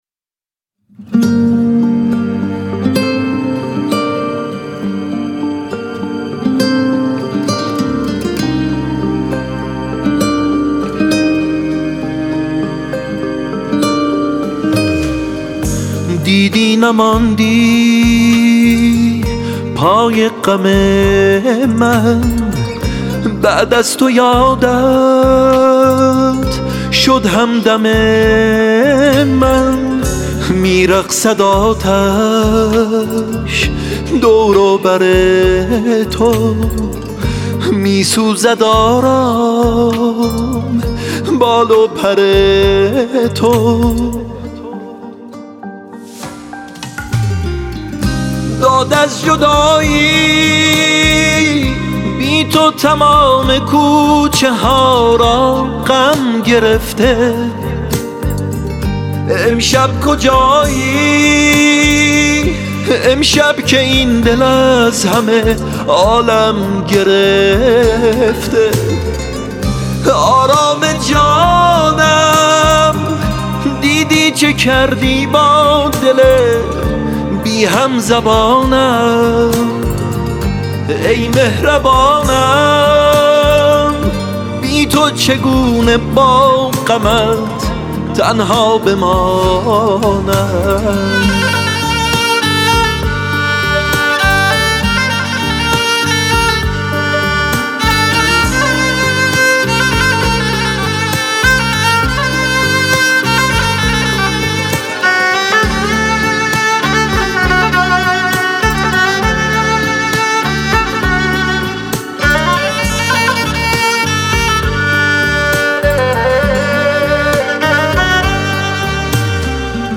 کمانچه
گیتار